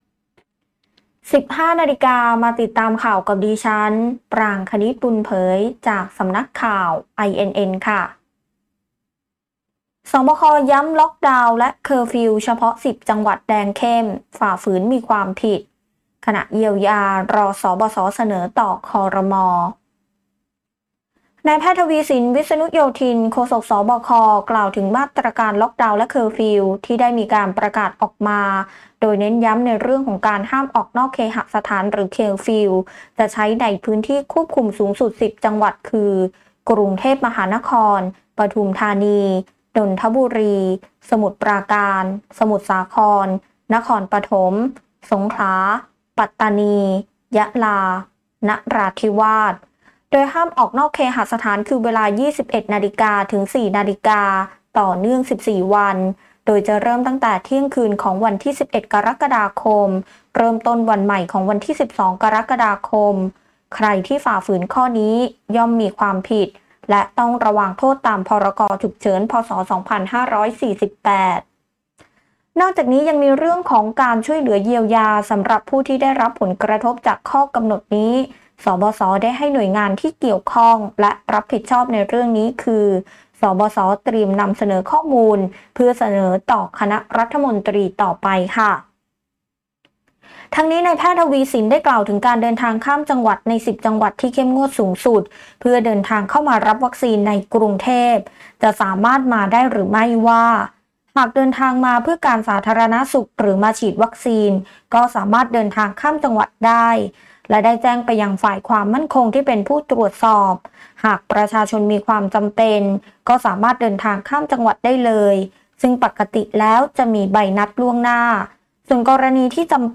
คลิปข่าวต้นชั่วโมง
ข่าวต้นชั่วโมง 15.00 น.